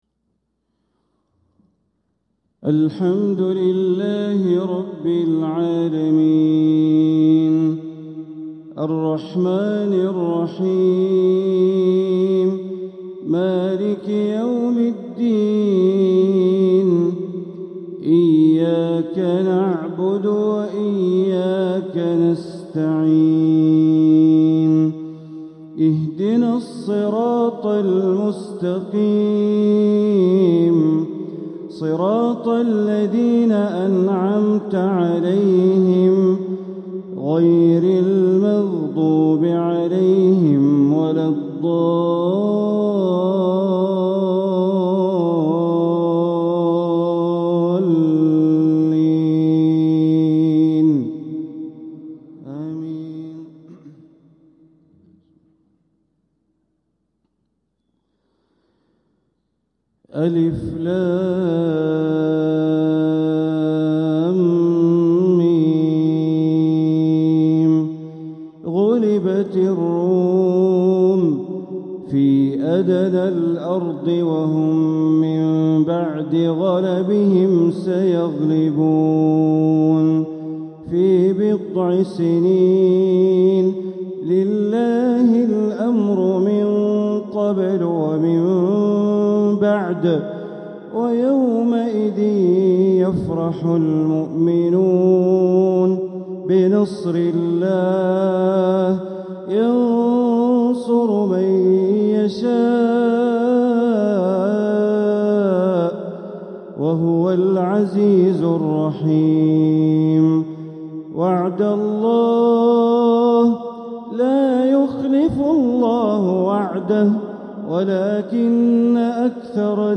تلاوة لفواتح سورة الروم ١-٢٧ | فجر الأربعاء ١٨ ربيع الأول ١٤٤٧ > 1447هـ > الفروض - تلاوات بندر بليلة